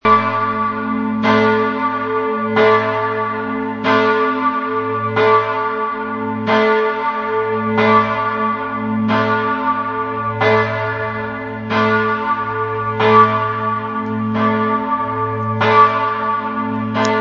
Dreifaltigkeits-Glocke
Ton: A (
Gewicht: 3600 kg
pfarreien_gommiswald_dreifaltigkeits-glocke.MP3